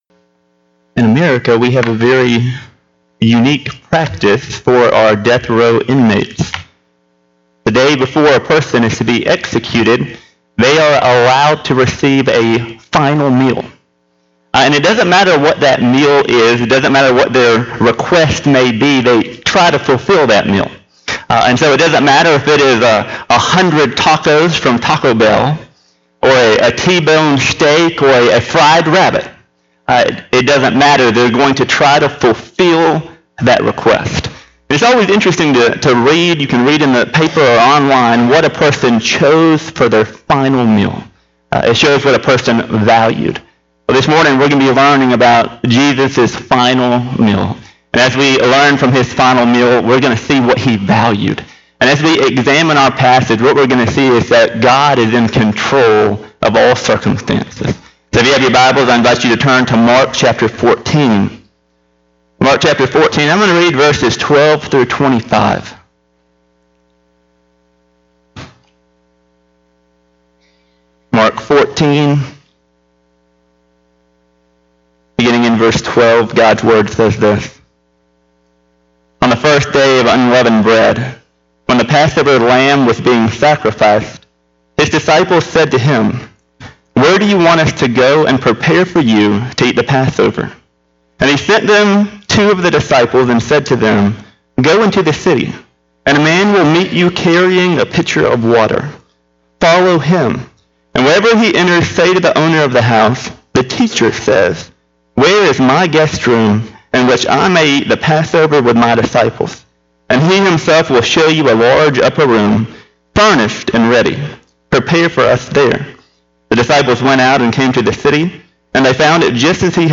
Audio Sermons: Videos of service can be seen on Facebook page - Trinity Baptist Church